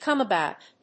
アクセントcòme abóut